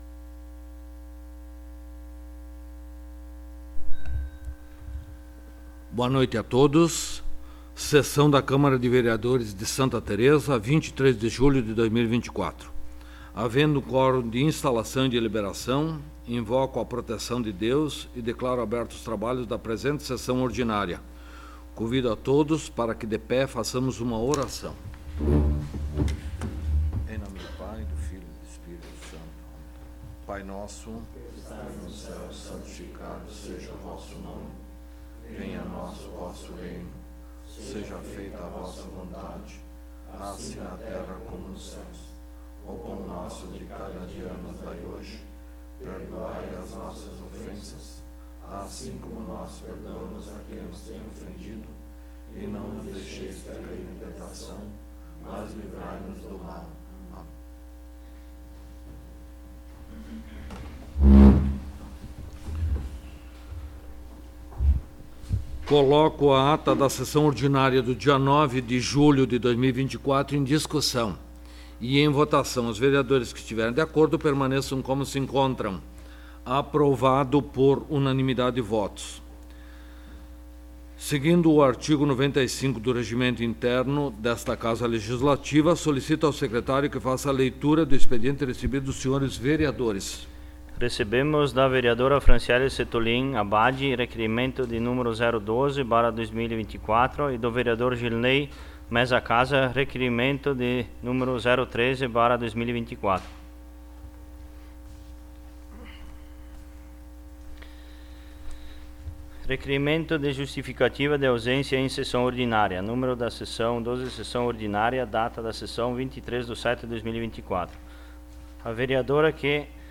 12º Sessão Ordinária de 2024
Local: Câmara Municipal de Vereadores de Santa Tereza